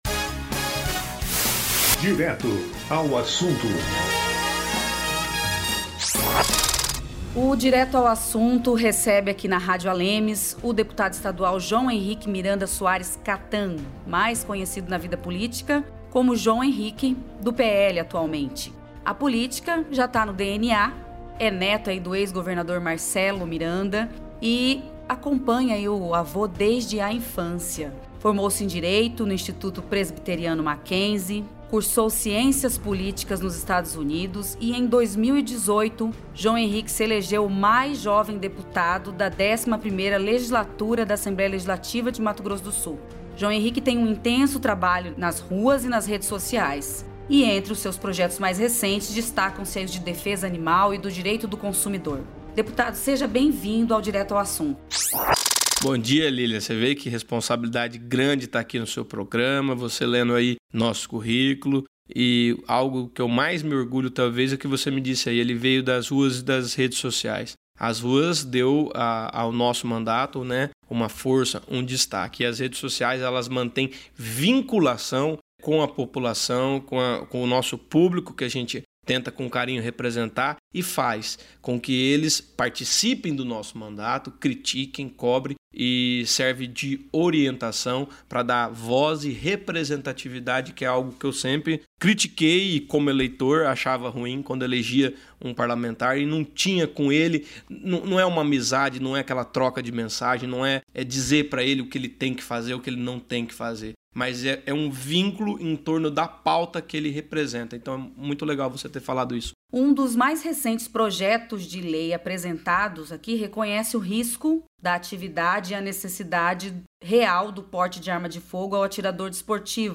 Deputado estadual João Henrique (PR) é o entrevistado dessa semana do Direto ao Assunto e explica para os ouvintes a importância de definir em lei estadual o risco da atividade dos Colecionadores, Atiradores e Caçadores (CAC) para possibilitar a aquisição do porte de armas, seguindo as normas federais e da Lei da Onça, que ressarce o produtor rural que tiver algum animal abatido pelo felino.